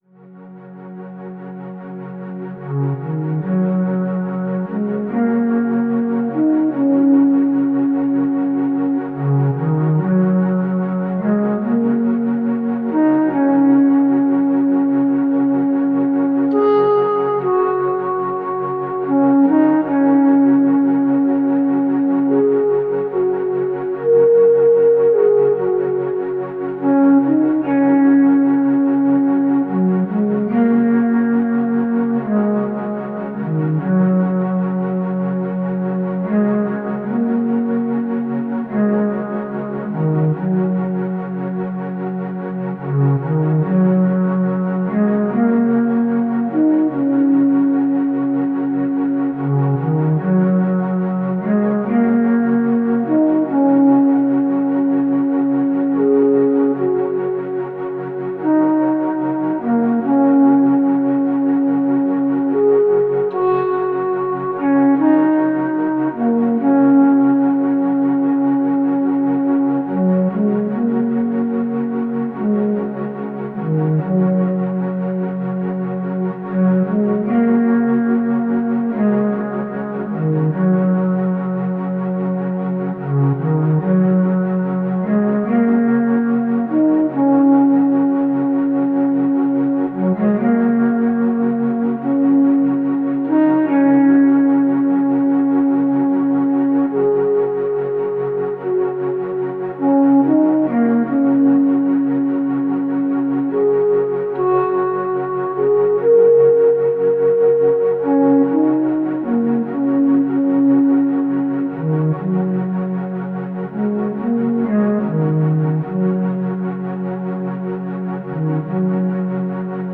Tempo: 37bpm / Datum: 15.05.2017